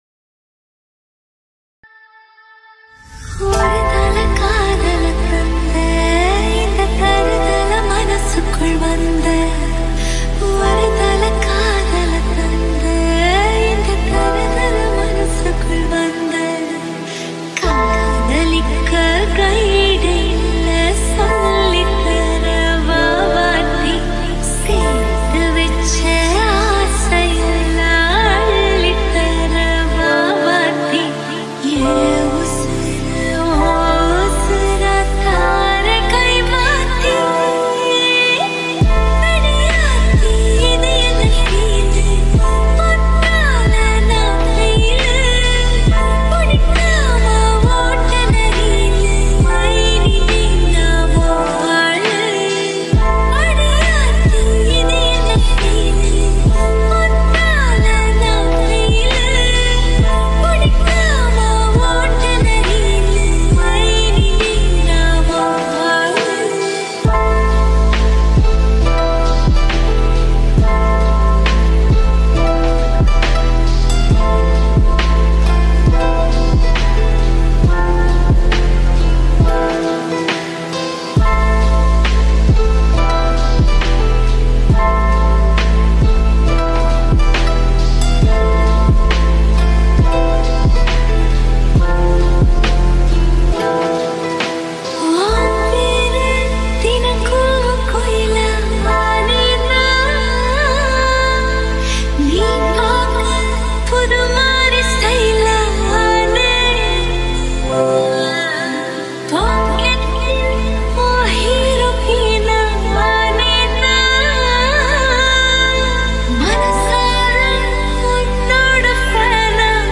Tamil Lofi Remixs